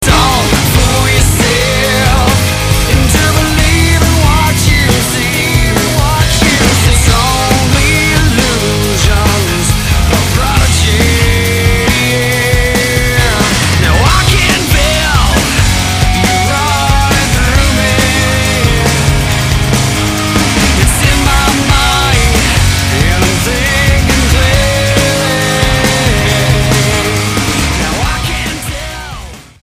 Rock Album
Style: Rock